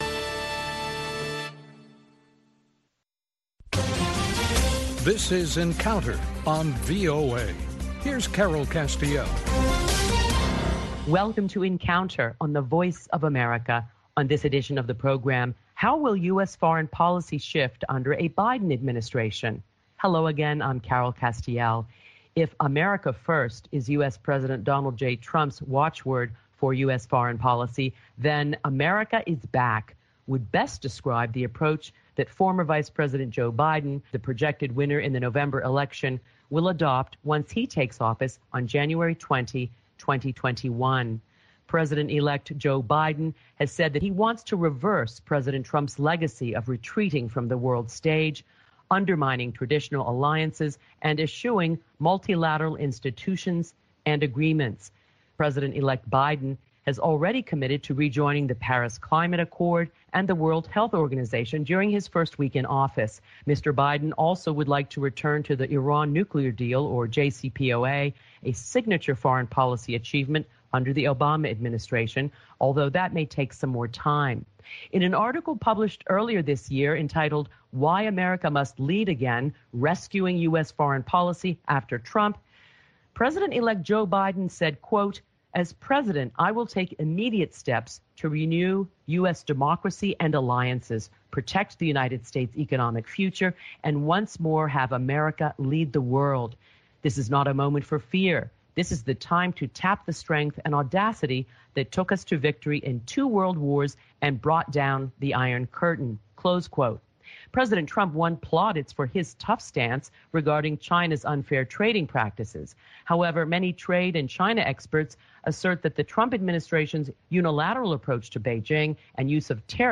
A panel of prominent Washington journalists deliberate the latest top stories of the week including the growing concern that the Republican Party’s refusal to acknowledge Biden's victory is impeding the incoming-administration’s transition.